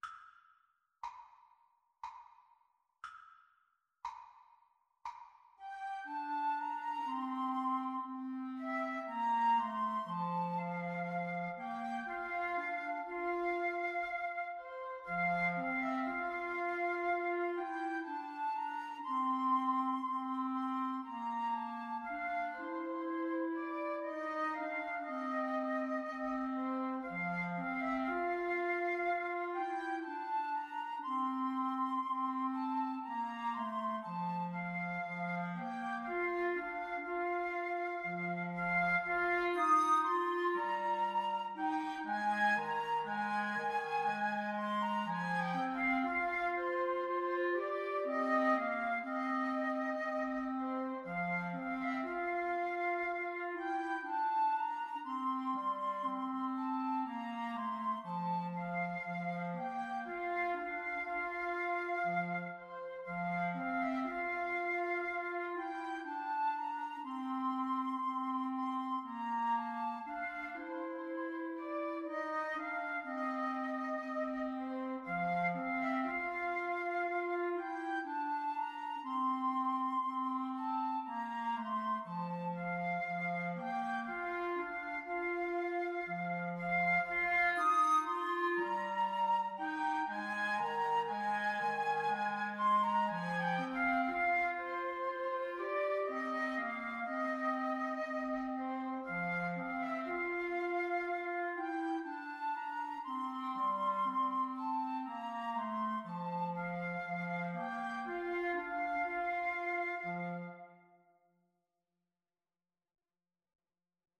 3/4 (View more 3/4 Music)
Slow, expressive =c.60